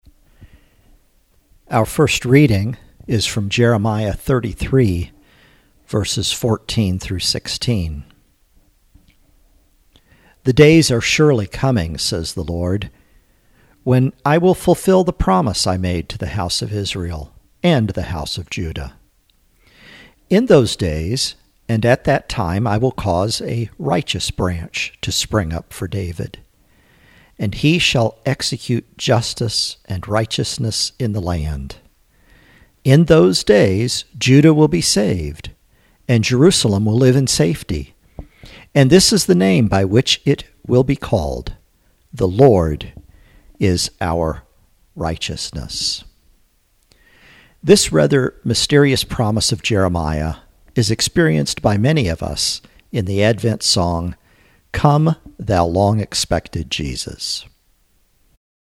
Reading: Jeremiah 33: 14-16